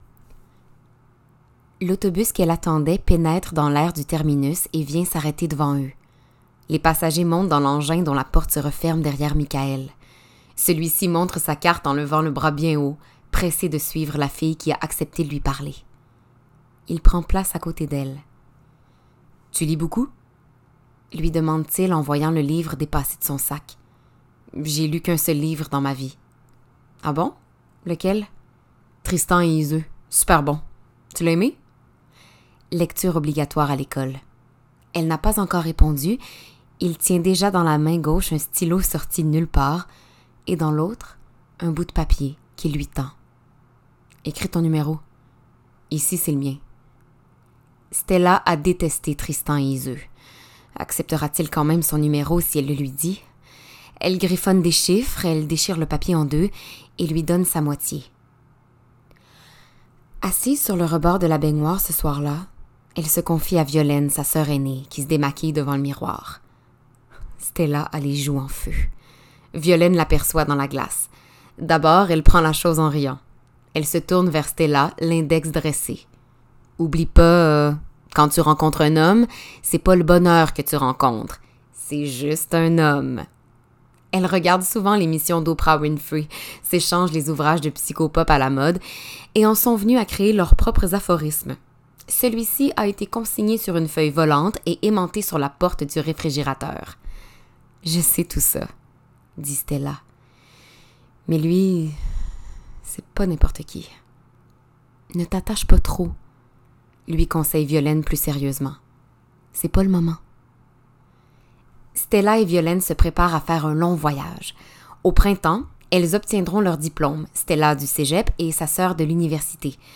Démos voix
Audio-livre Refaire l'amour
Narration